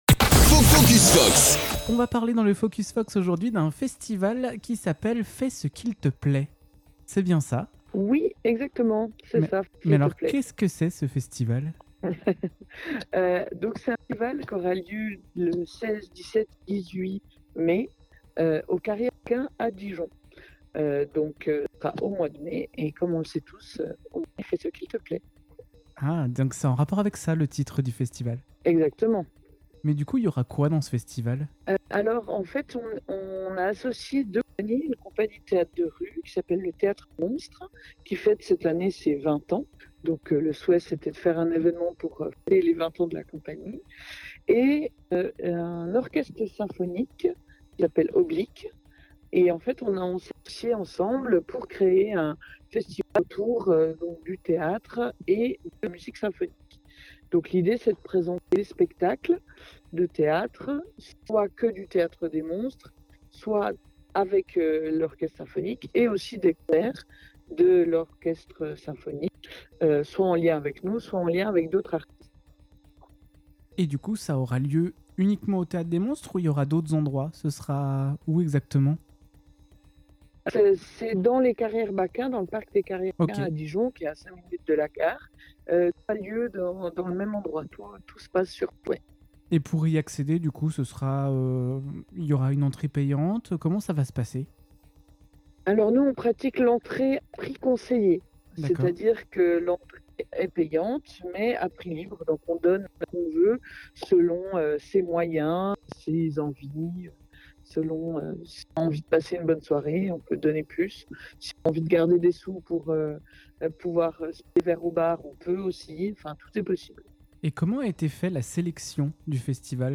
???? À écouter dans notre Focus Fox : entretien avec les organisateurs, retour sur les éditions précédentes et aperçu de cette nouvelle édition aussi libre que joyeuse.